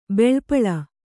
♪ beḷpaḷa